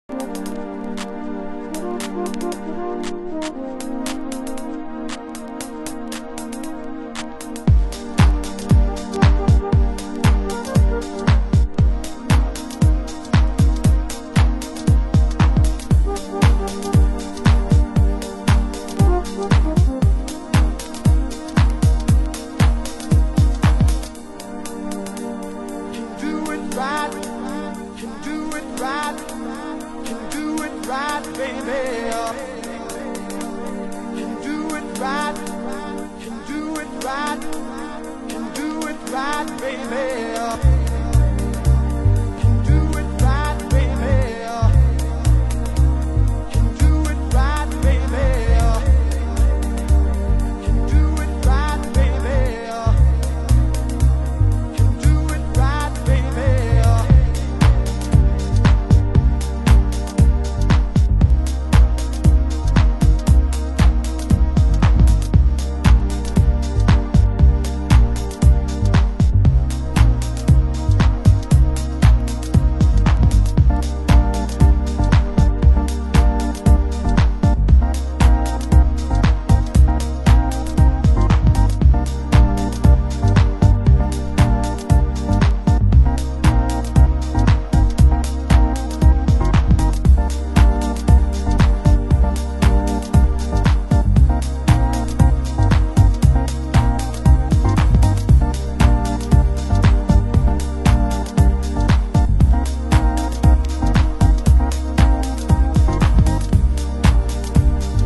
★90'S HOUSE LABEL
盤質：盤面良好ですが、少しチリパチノイズ有